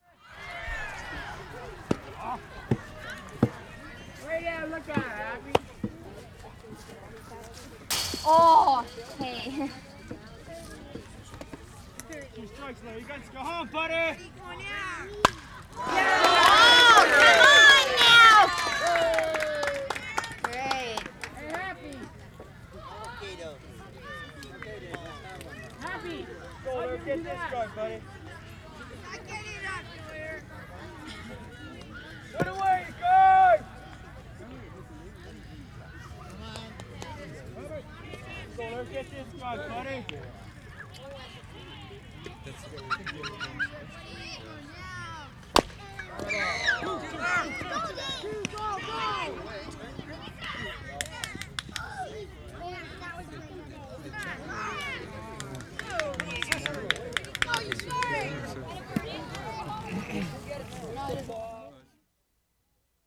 WORLD SOUNDSCAPE PROJECT TAPE LIBRARY
SOFTBALL GAME June 20, 1973
*2. Good close-up voices and good hits. Opens with applause.